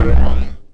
rain01.mp3